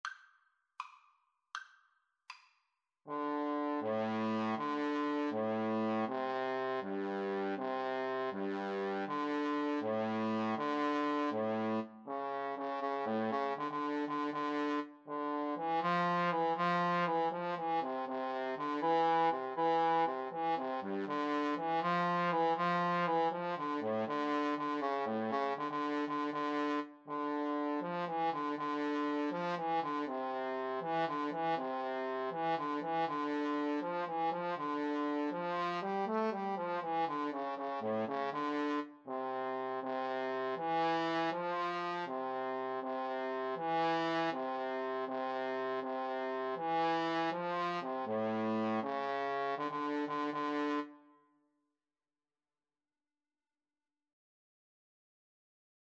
D minor (Sounding Pitch) E minor (Trumpet in Bb) (View more D minor Music for Trumpet-Trombone Duet )